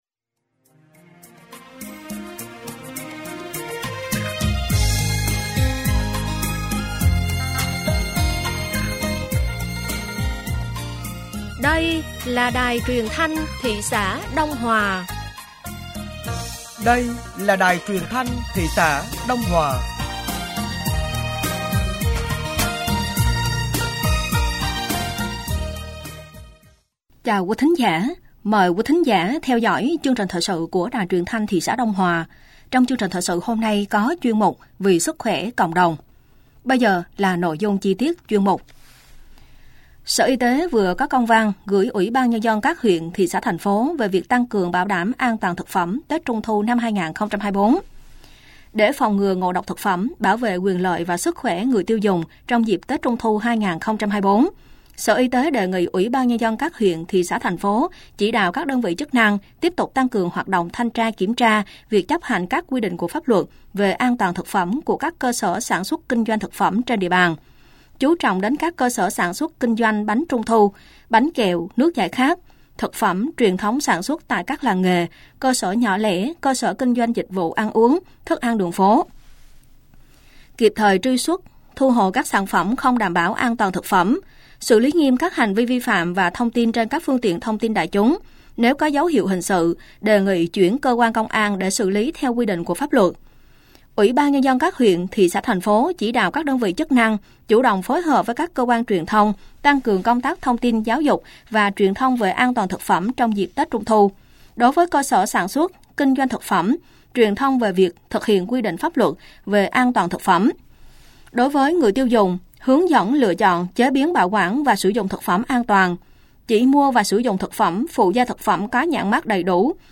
Thời sự tối ngày 27 và sáng ngày 28 tháng 8 năm 2024